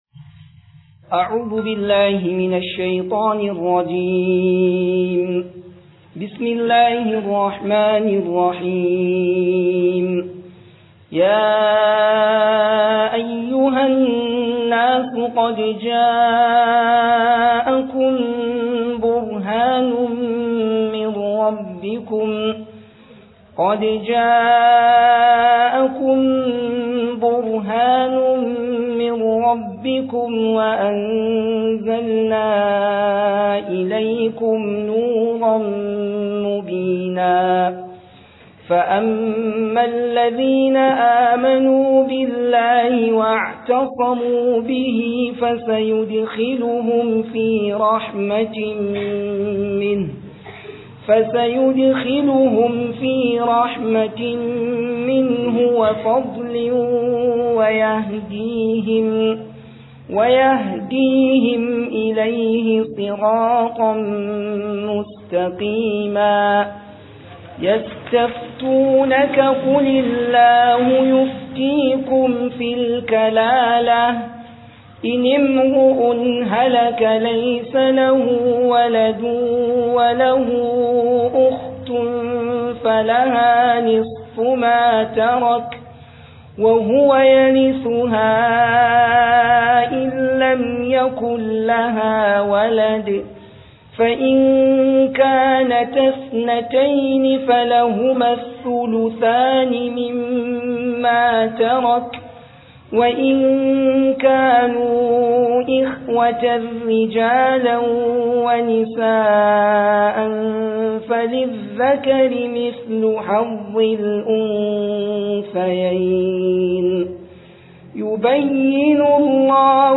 002 RAMADAN TAFSIR